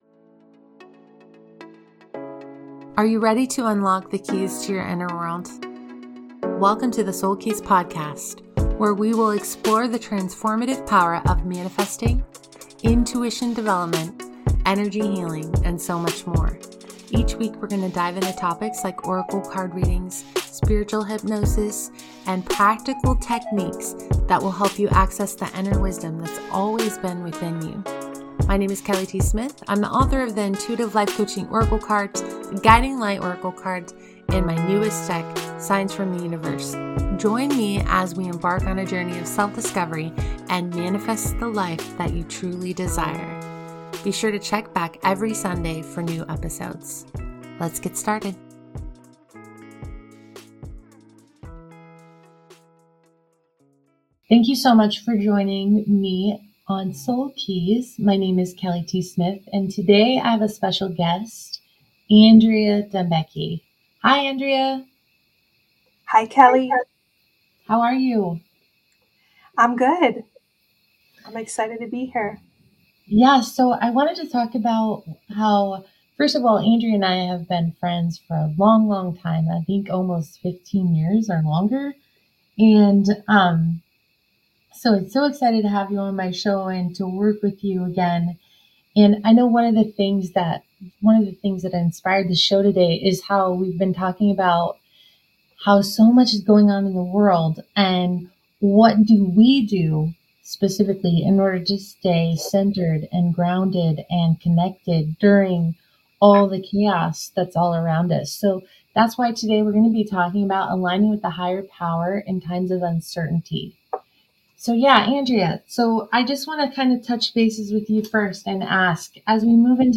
This heartfelt conversation offers guidance, energy insights, and practical tools to help you reconnect with your inner compass and the Higher Power that guides us all.